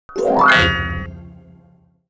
UI_SFX_Pack_61_13.wav